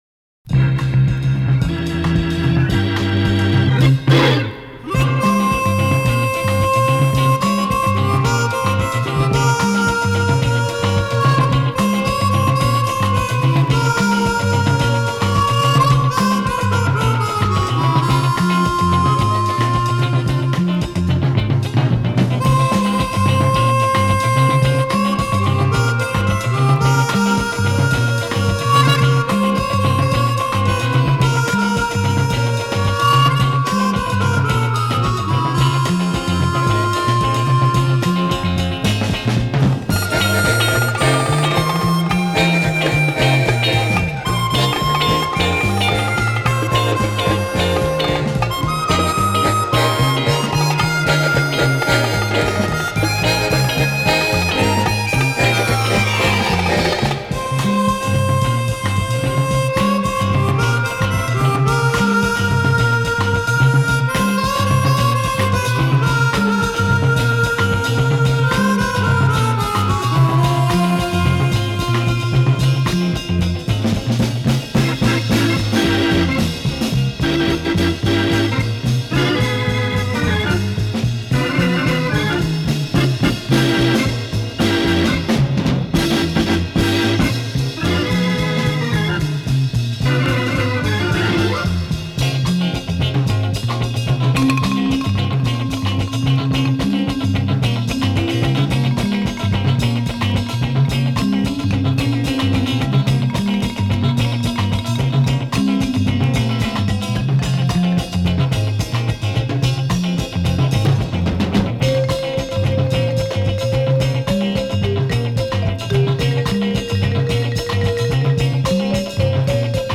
джазовый стандарт
На этой записи с выделенной губной гармошкой и оркестром.